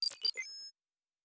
pgs/Assets/Audio/Sci-Fi Sounds/Electric/Data Calculating 1_2.wav at master
Data Calculating 1_2.wav